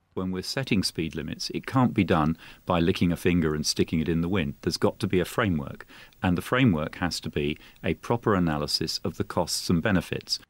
Philip Hammond speaking about the idea of 80mph speed limits on motorways.